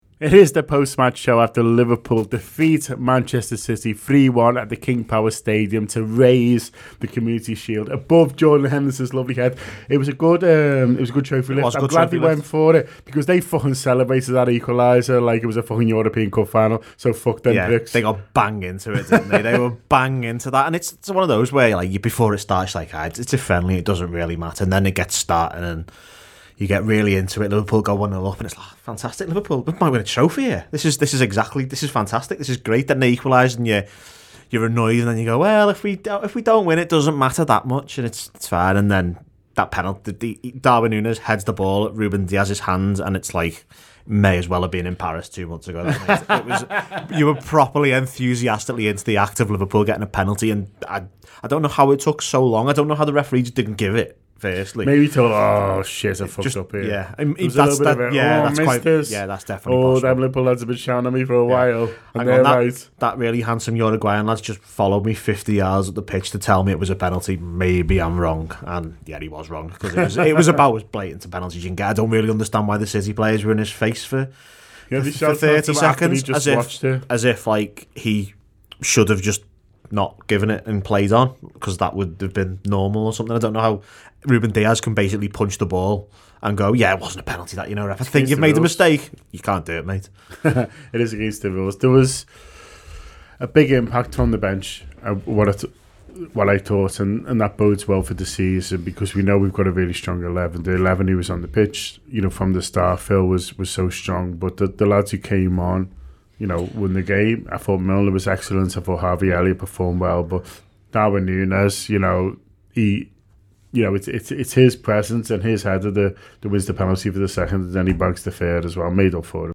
The Anfield Wrap’s post-match reaction podcast after Liverpool 3 Manchester City 1 in the Community Shield at Leicester’s King Power Stadium.